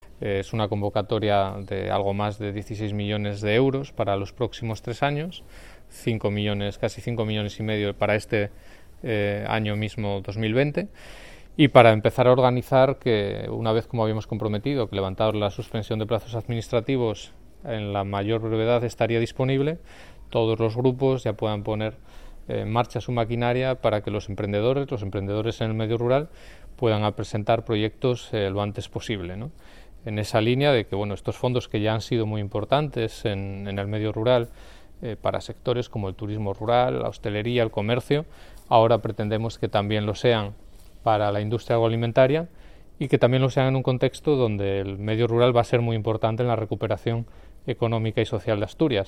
Declaración del Consejero sobre el LEADER